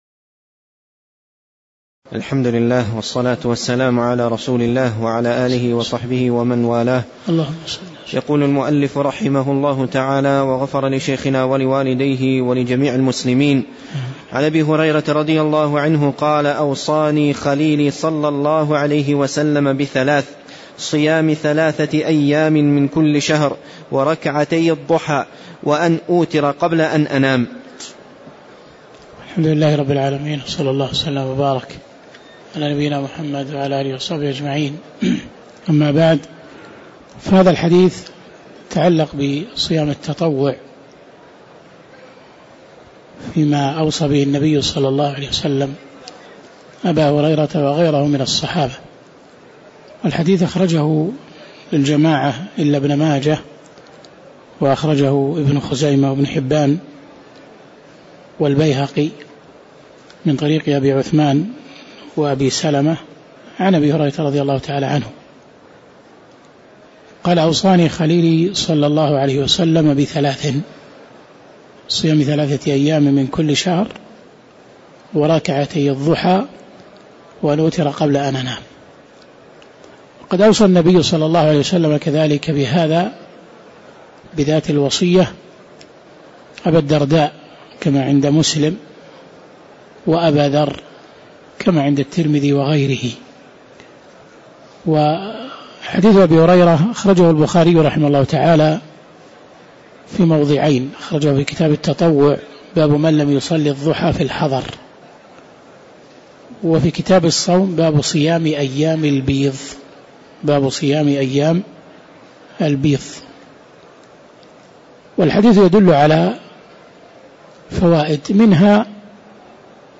تاريخ النشر ٢٧ رجب ١٤٣٧ هـ المكان: المسجد النبوي الشيخ